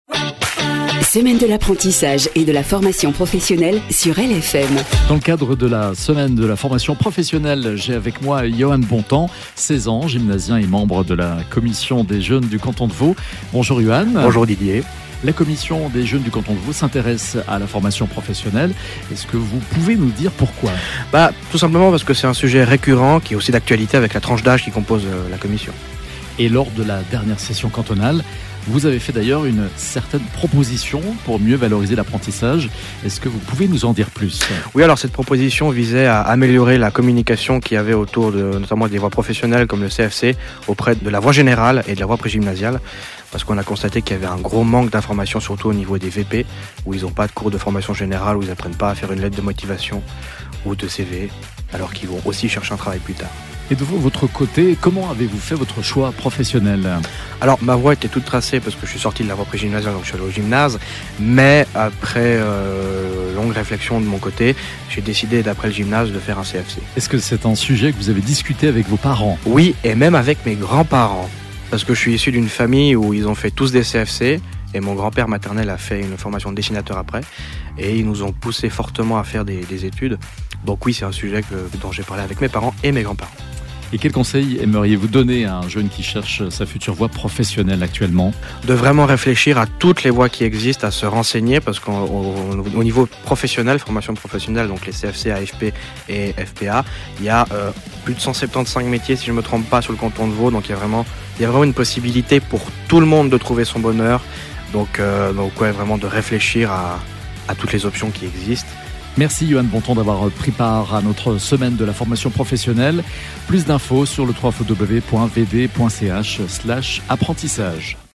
Dans le canton de Vaud, des invités parleront de leur expérience ou de leur vision du choix d’un apprentissage, chaque jour à 16h18 et à 18h48 sur LFM.
Programme des interviews